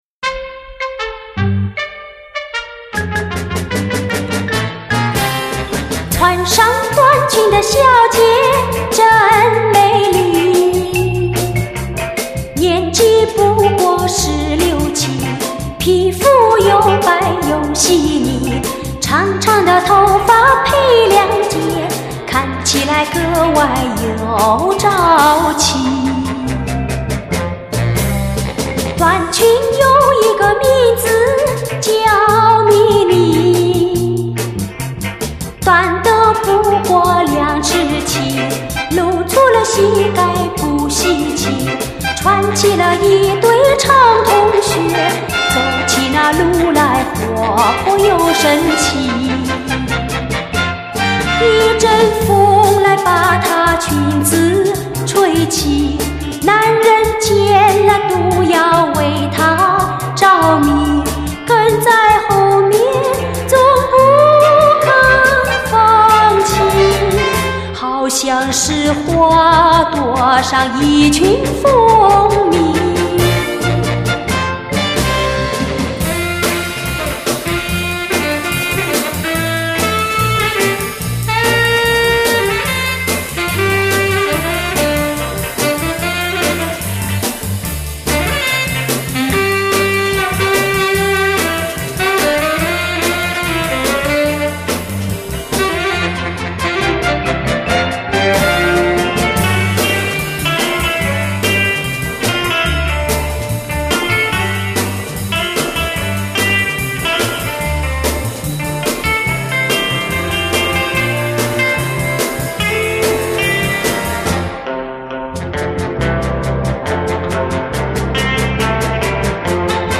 音乐风格: 民谣